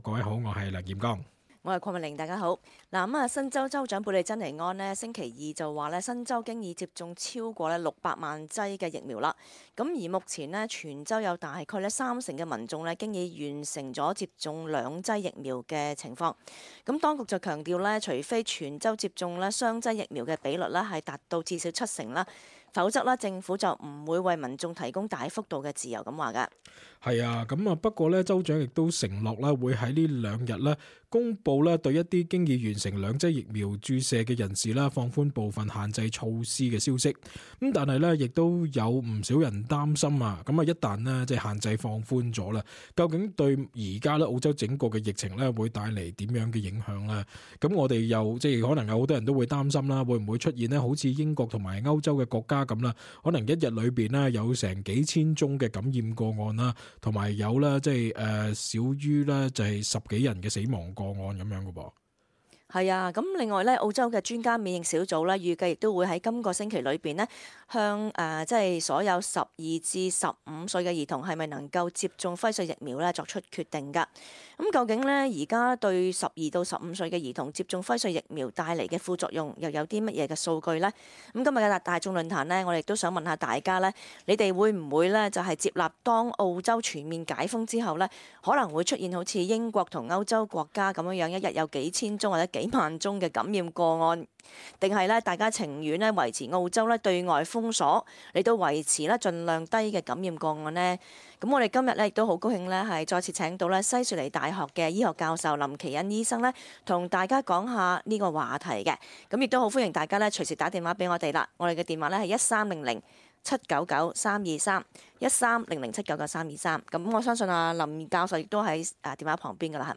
cantonese_talkback_iupload_final_aug_28.mp3